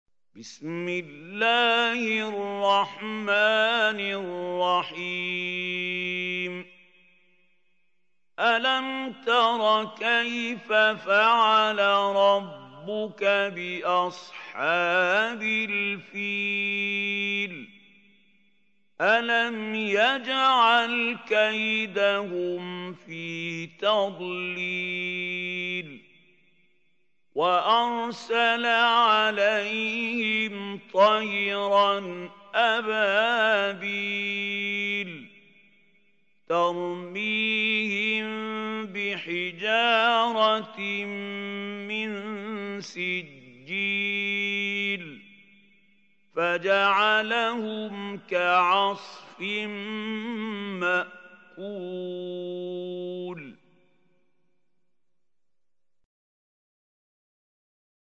سورة الفيل | القارئ محمود خليل الحصري